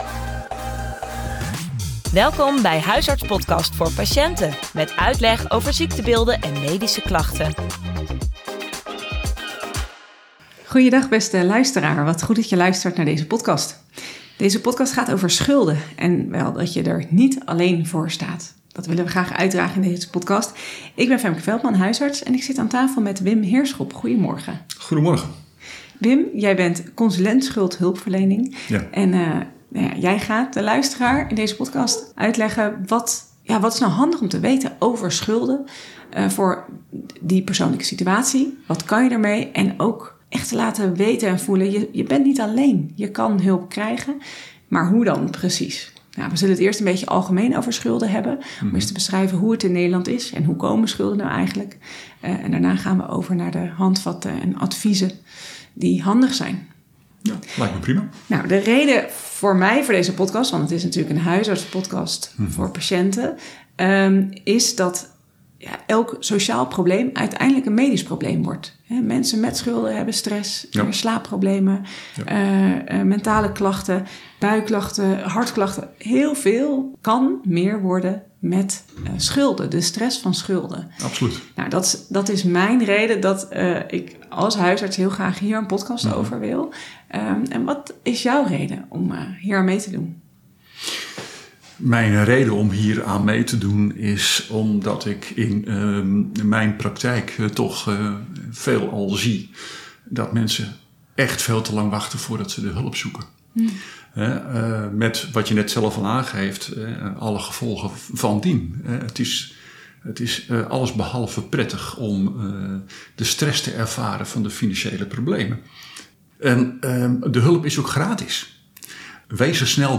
in gesprek over hoe het wél kan, met veel praktische handvatten en tips.